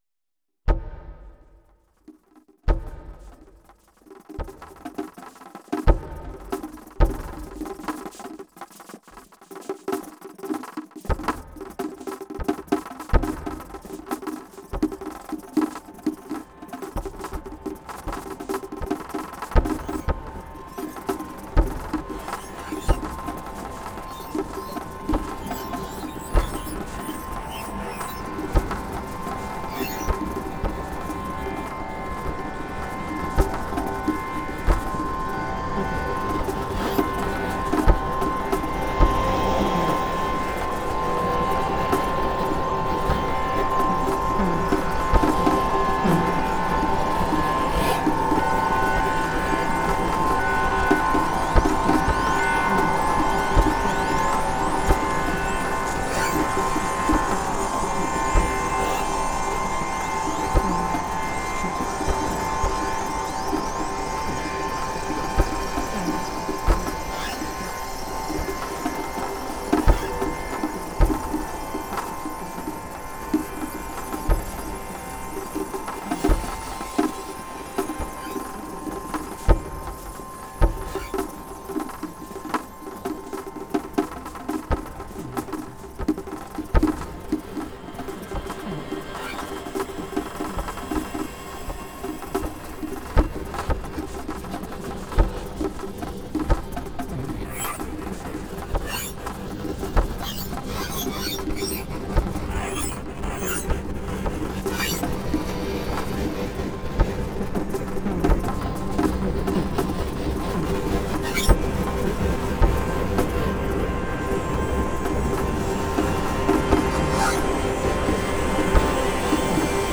コーランを唱える祈りのような声、のたうち回る歪んだ咆哮、
ある種のジャズやエレクトロニカ、またワールド・ミュージックに近似性を持ち、
エロティックでパワフルで、ホルモン分泌が活発になる感じ。